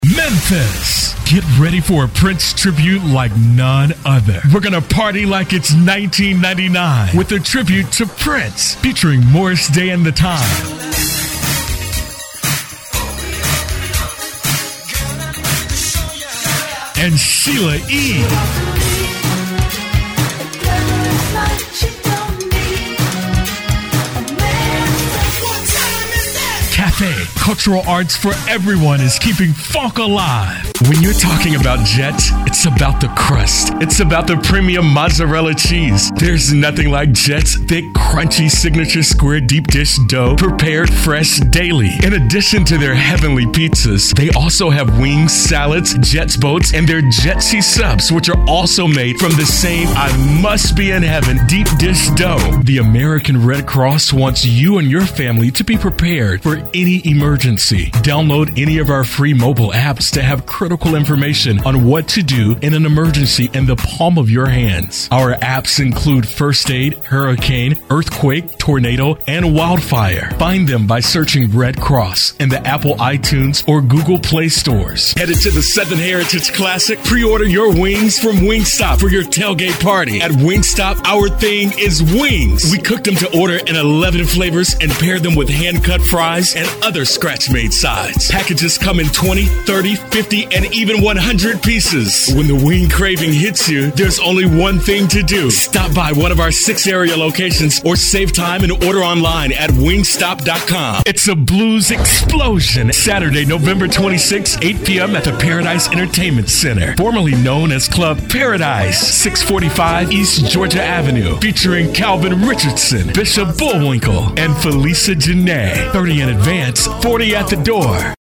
Commercial Production: multi-format: